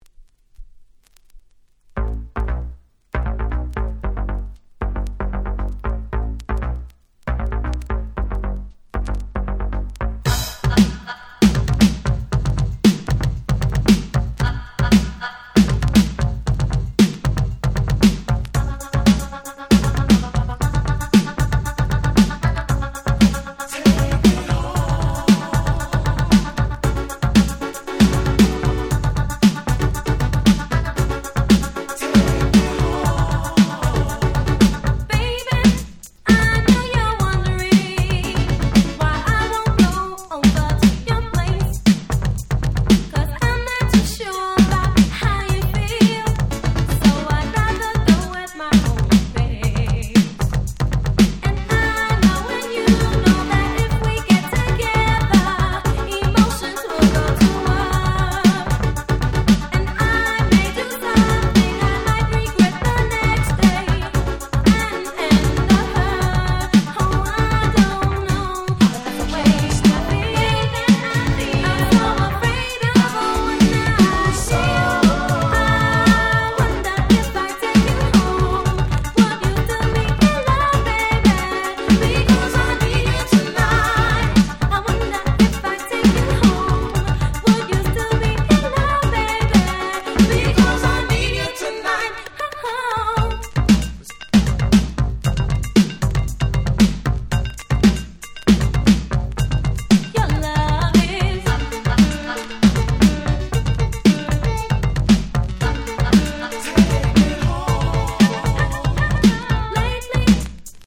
84' Super Hit Disco !!